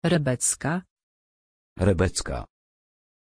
Pronunția numelui Rebecka
pronunciation-rebecka-pl.mp3